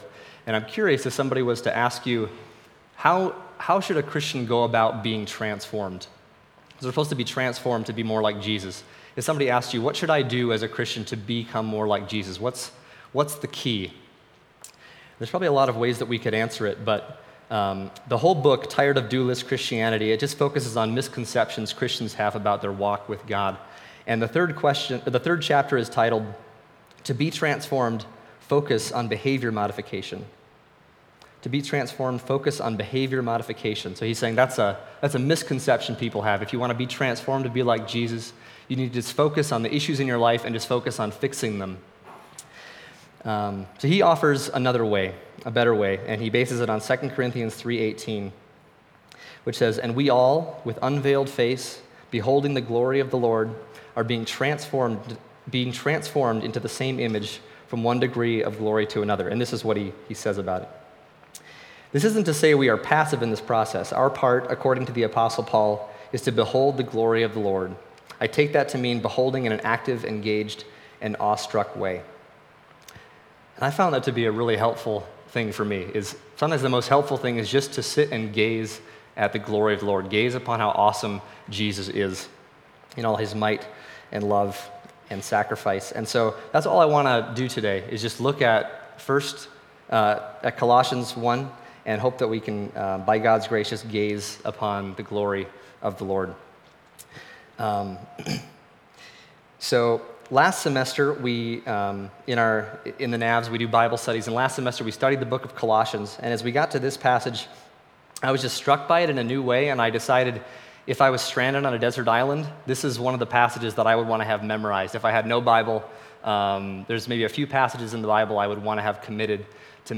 Passage: Colossians 1:15-23 Service Type: Sunday Morning